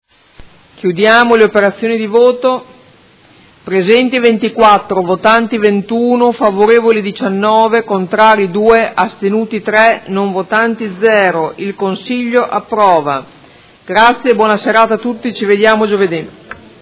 Presidentessa — Sito Audio Consiglio Comunale
Seduta del 21/06/2018 Mette ai voti Odg n. 93529. Sviluppo trasporto pubblico locale. Chiude i lavori del Consiglio Comunale.